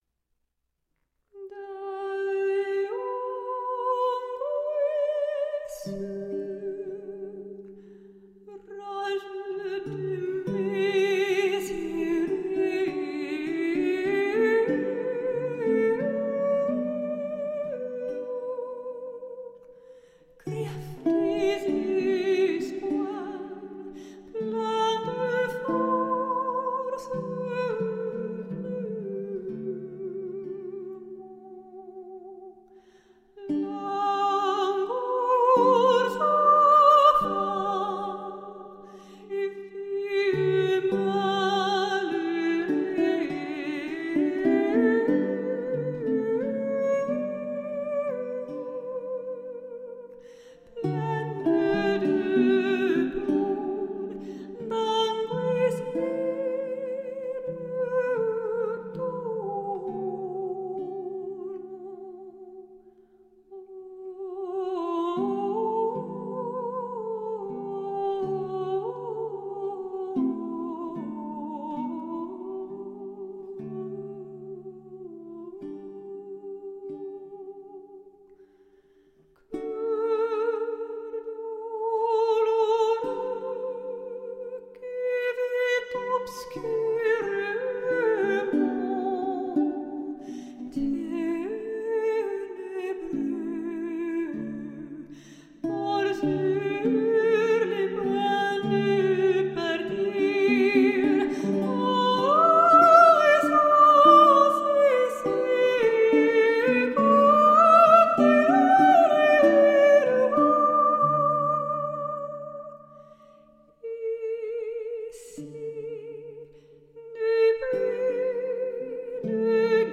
Late-medieval vocal and instrumental music.